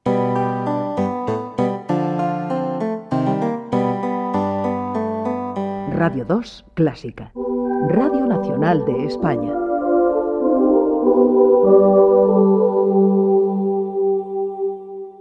Indicatiu com Radio 2 Clásica